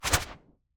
WHOOSH_AIRY_FLUTTER_01.wav